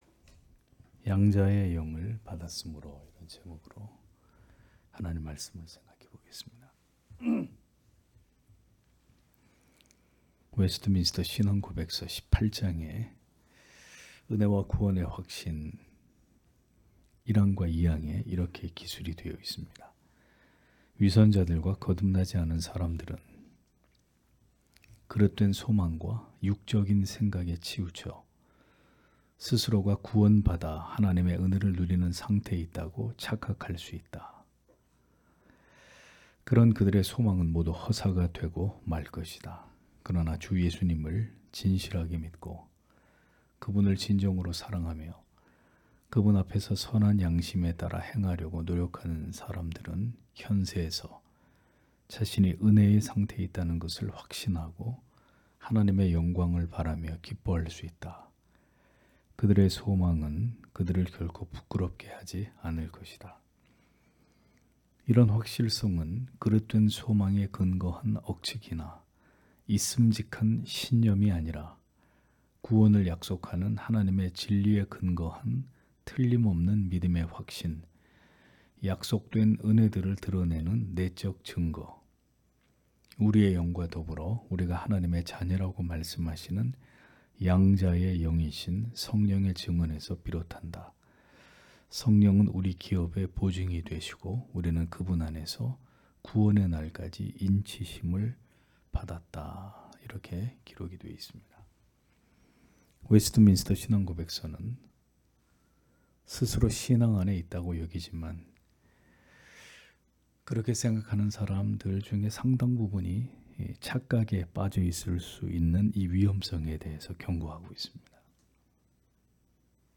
금요기도회